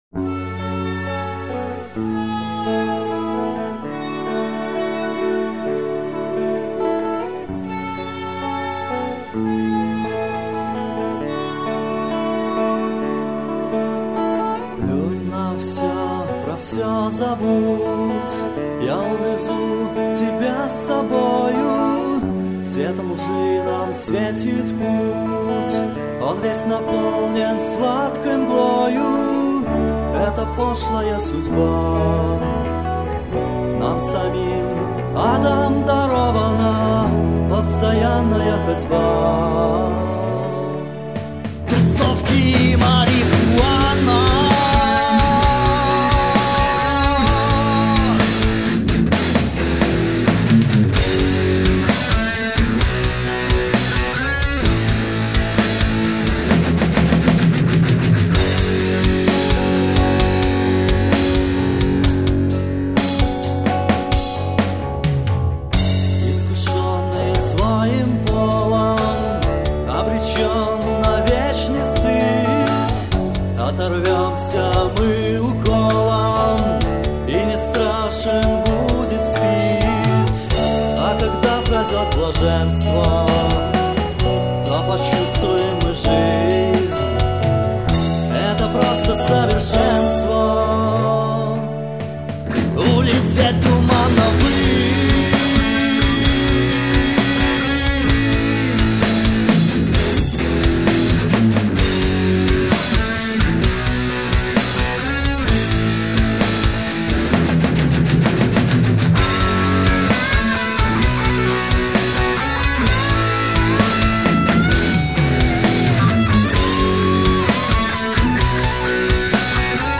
Альбом записан в стиле heavy metal, тексты на русском языке.
ударные
бас
ритм-гитара
соло-гитара, бэк-вокал
вокал